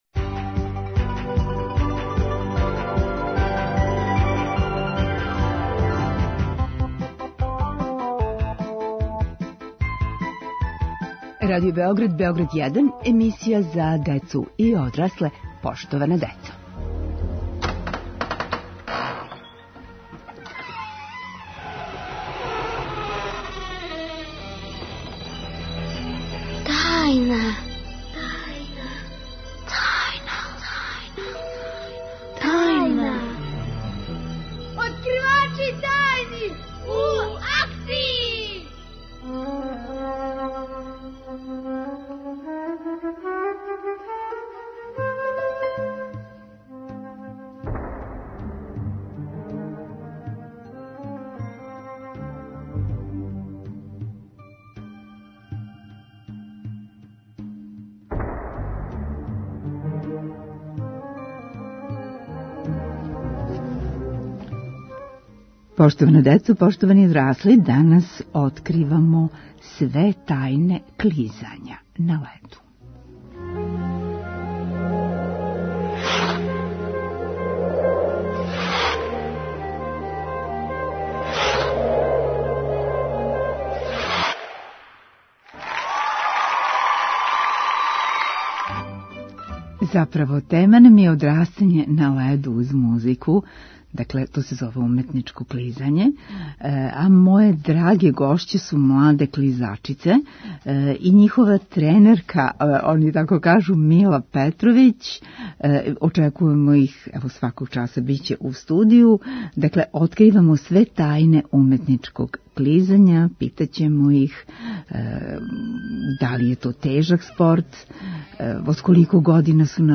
Откривамо све тајне уметничког клизања. Откривачи су: млади клизачи, њихов тренер, али и Заштолог, Зоотајнолог и Клизањолог.